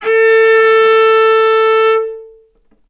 vc-A4-ff.AIF